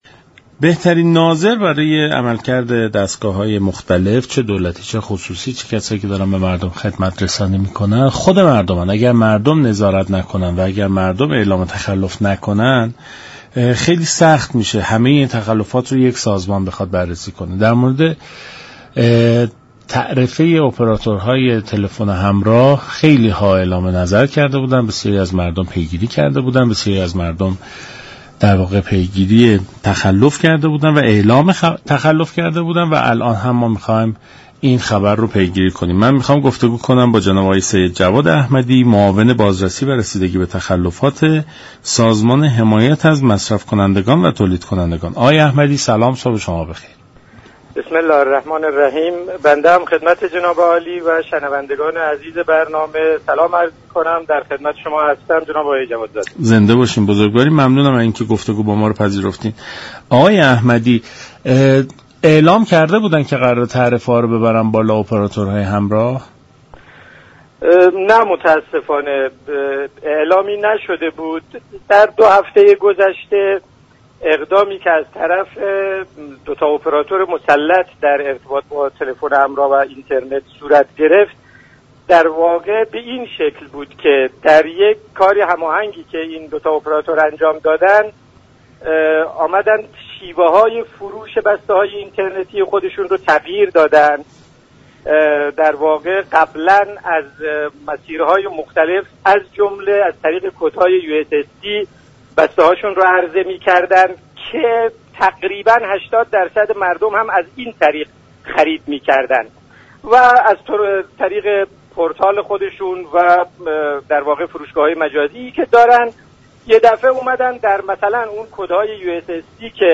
در رادیو ایران مطرح شد: افزایش تعرفه های اپراتورهای تلفن همراه با اعلام قبلی صورت نگرفته است
دریافت فایل به گزارش شبكه رادیویی ایران، سید جواد احمدی معاون بازرسی و رسیدگی به تخلفات سازمان حمایت از مصرف كنندگان و تولید كنندگان در برنامه سلام صبح بخیر با بیان اینكه افزایش تعرفه های اپراتورهای همراه با اعلام قبلی صورت نگرفته است، گفت: اقدامات صورت گرفته بر اساس مصوبات كمیسیون تنظیم و مقررات ارتباطات رادیویی به تایید سازمان تنظیم و مقررات ارتباطات رادیویی نرسیده است و این عدم تایید، نارضایتی ها را به دنبال داشته است.